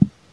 Tags: 808 drum cat kick kicks hip-hop